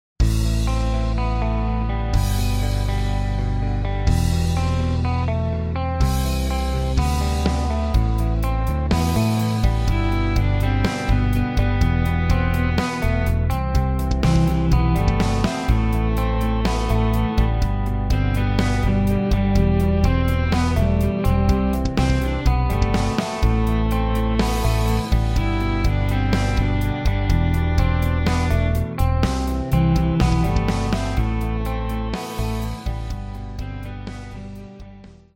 Recueil pour Trompette ou cornet